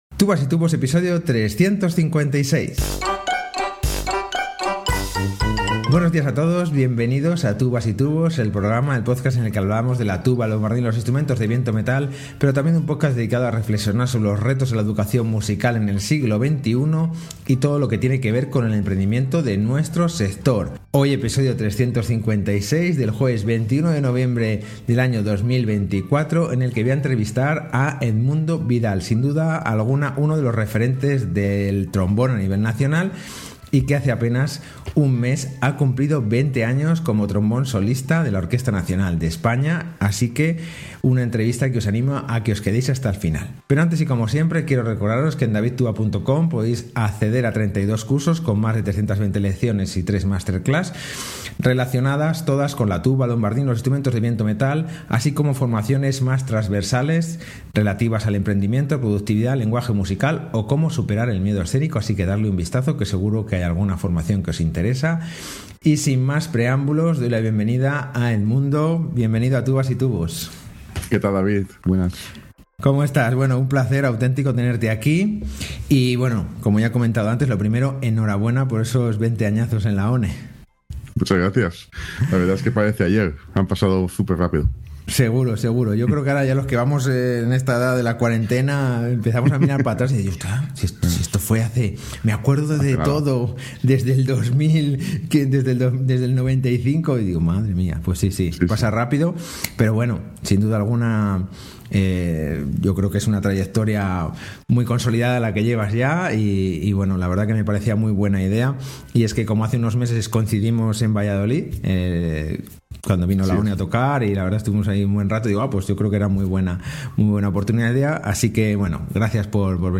Super entrevista